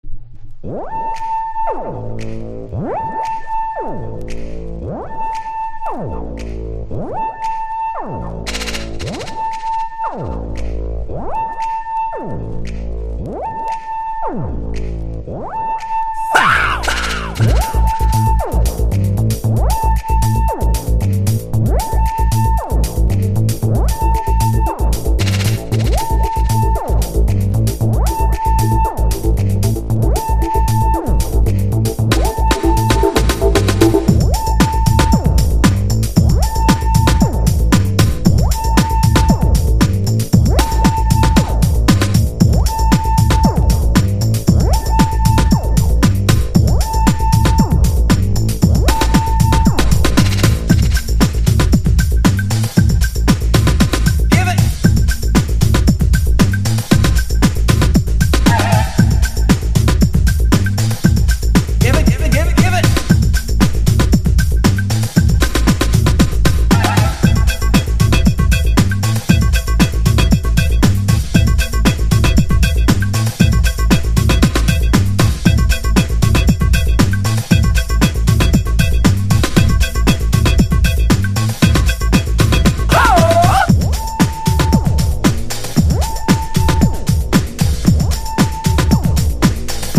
FUNK / DEEP FUNK# BREAK BEATS / BIG BEAT
(EXTENDED REMIX VERSION)